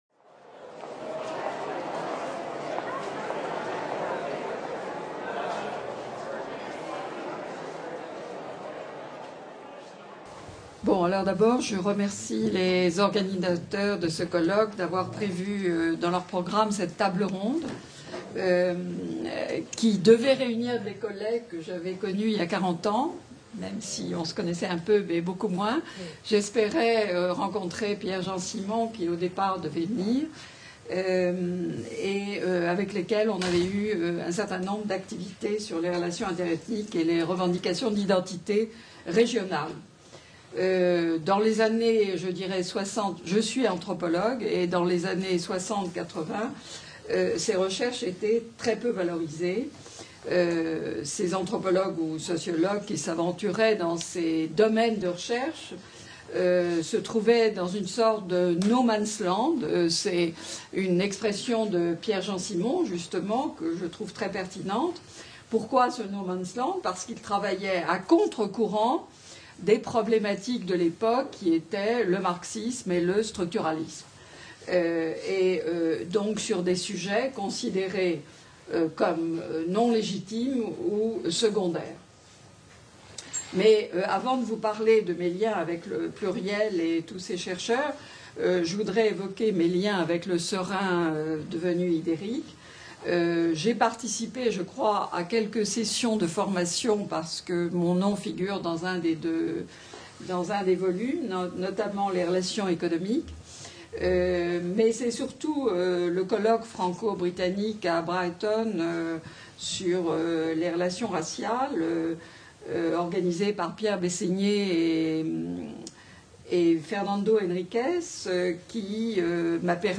Table ronde : les recherches sur les relations interethniques dans leur contexte théorique et institutionnel : héritages, passerelles, ruptures, discontinuités
Colloque du cinquantenaire de la création du CERIN, Centre d’études des relations interethniques/ Ideric, Institut d’études et de recherches interethniques et interculturelles 3 et 4 mai 2017, MSHS, Université Nice Sophia Antipolis